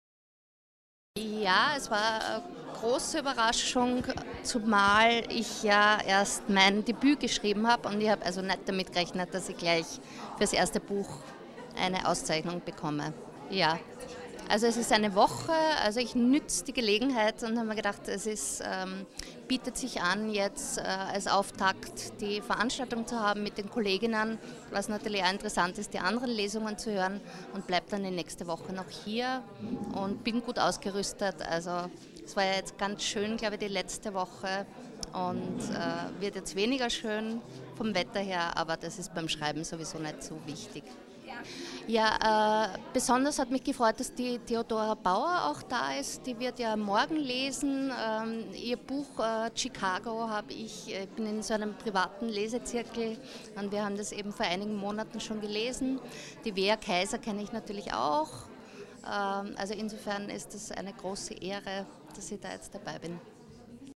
Interviews (ROH) mit.